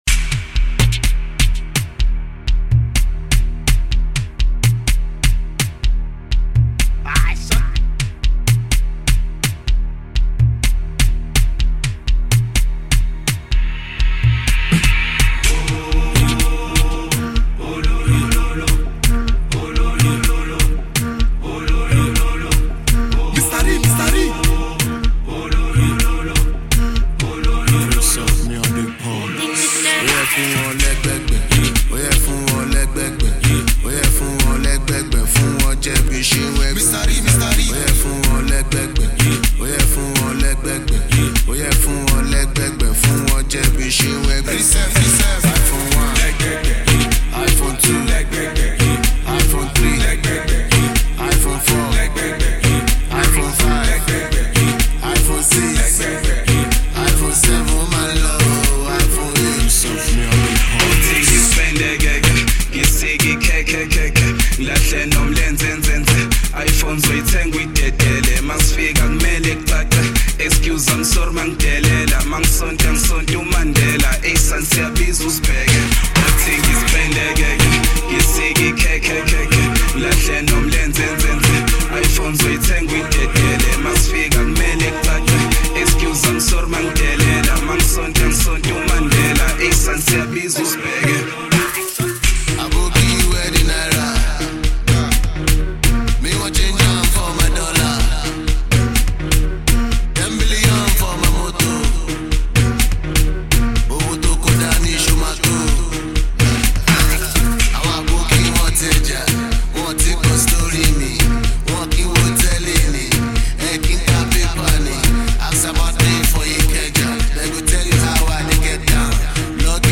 Gqom mix